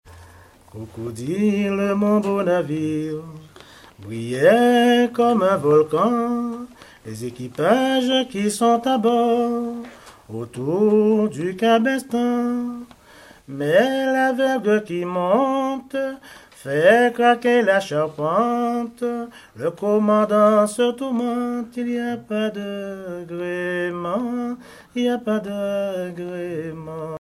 Genre strophique
chansons anciennes recueillies en Guadeloupe
Pièce musicale inédite